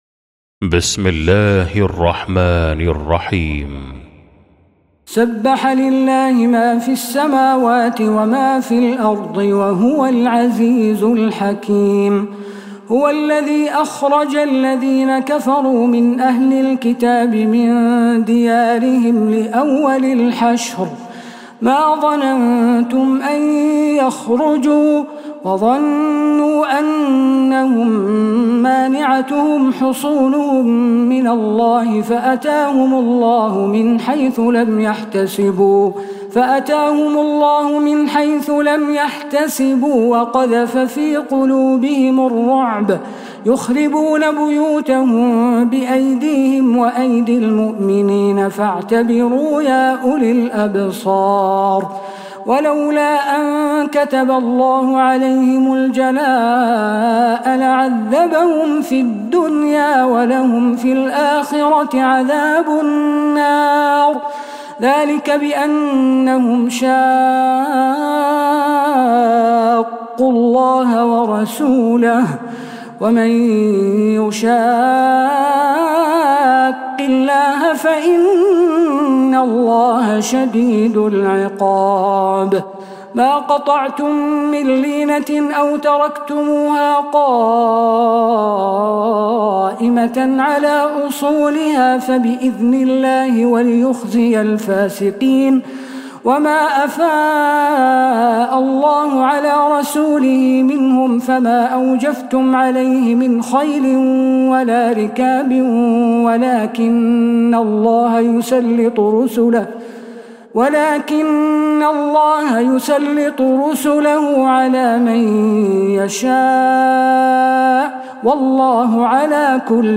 سورة الحشرSurah Al-Hashr > مصحف تراويح الحرم النبوي عام 1446هـ > المصحف - تلاوات الحرمين